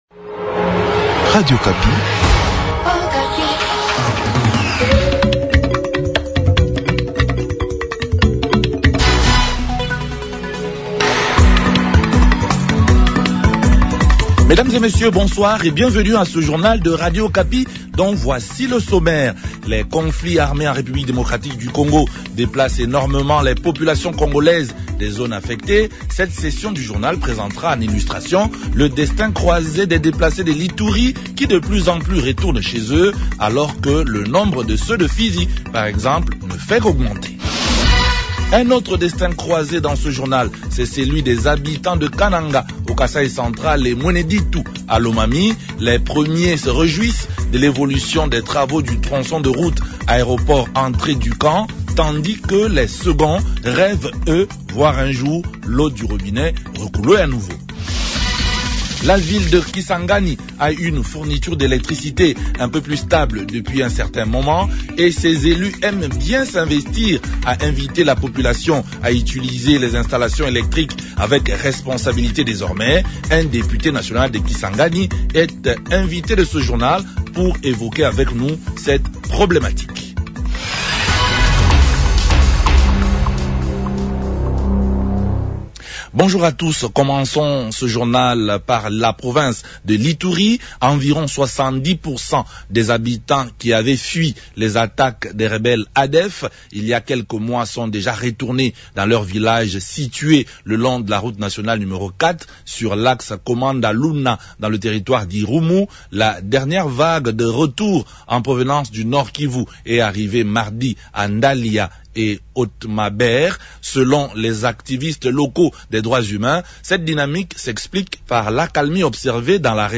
Journal francais Soir
Un député national de Kisangani est notre invite ce soir pour évoquer avec nous cette problématique.